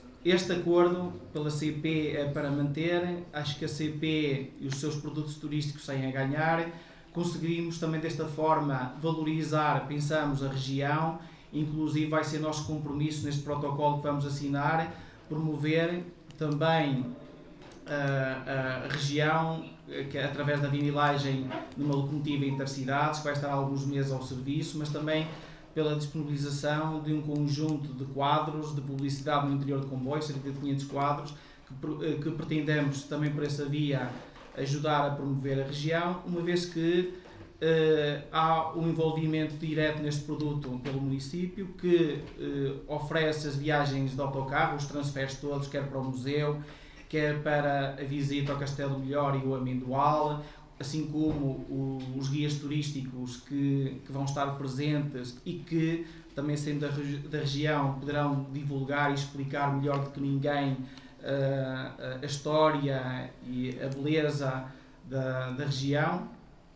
Na conferência de imprensa, realizada no salão nobre da Câmara Municipal de Vila Nova de Foz Côa, no passado dia 7 de fevereiro, para a apresentação do programa das Amendoeiras em Flor, também foi promovida a Rota das Amendoeiras da CP e foi assinado um protocolo entre esta empresa de comboios e a autarquia, nesta que é a rota mais antiga.